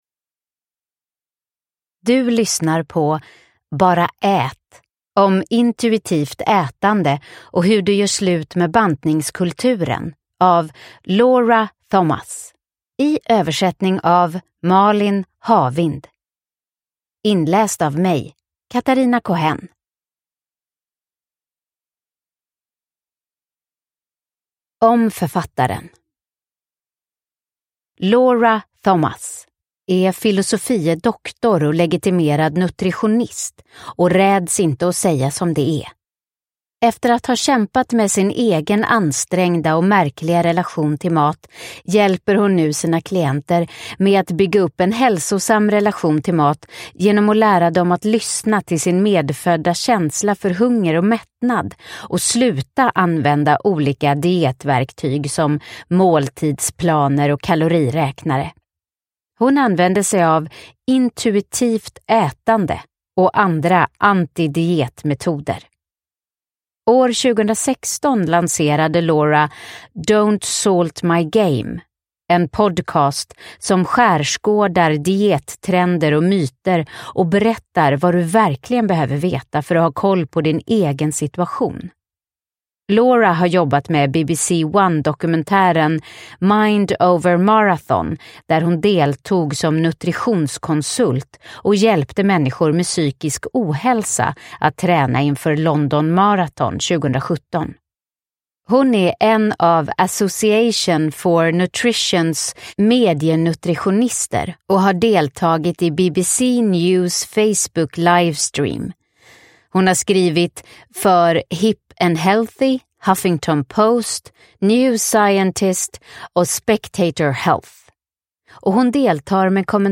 Bara ät! : om intuitivt ätande och hur du slutar krångla med maten – Ljudbok – Laddas ner